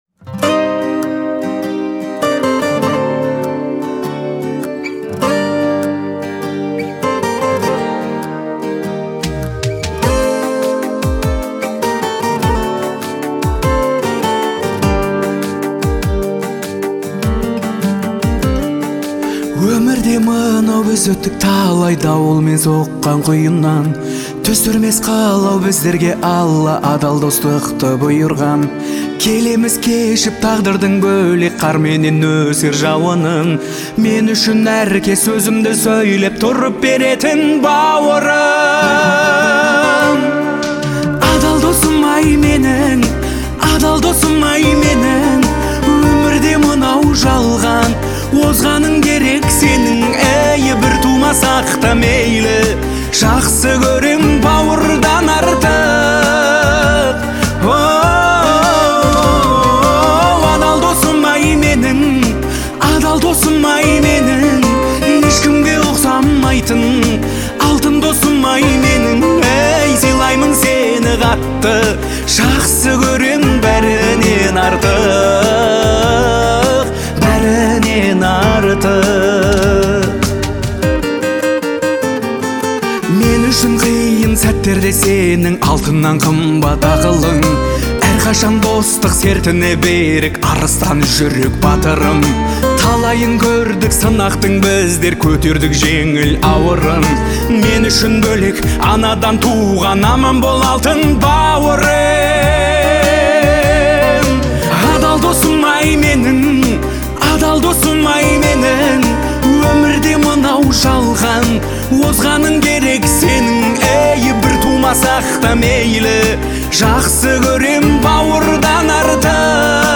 Трек размещён в разделе Казахская музыка.